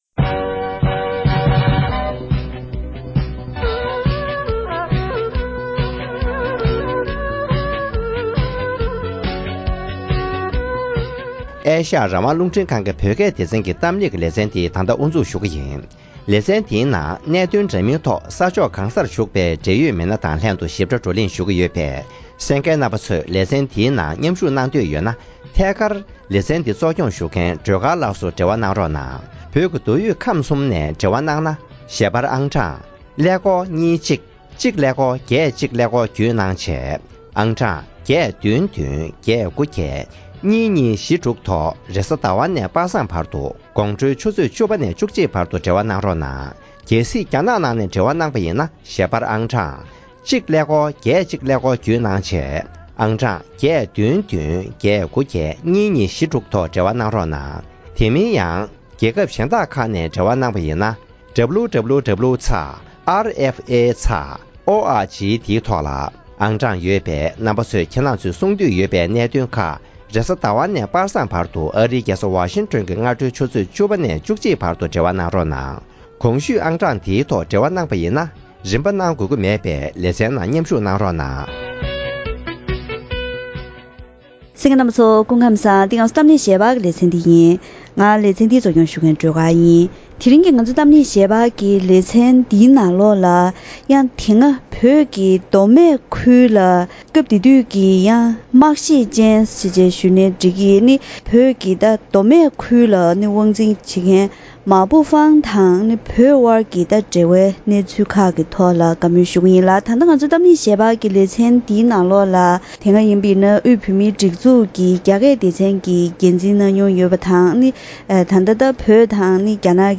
༄༅། །ཐེངས་འདིའི་གཏམ་གླེང་ལེ་ཚན་ནང་བོད་མདོ་སྨད་ཁུལ་དུ་བོད་མི་དམར་གསོད་དང་དགོན་པར་རྩ་གཏོར་བཏང་ནས་གྲགས་པའི་ཟི་ལིང་གི་རྒྱ་ཁ་ཆེ་མཱ་པུའུ་ཧྥང་གིས་བོད་མིར་དཀའ་ངལ་ཇི་འདྲ་བཟོས་སྐོར་ལོ་རྒྱུས་ལ་ཕྱིར་དྲན་ཞུས་པ་ཞིག་གསན་རོགས་གནང་།།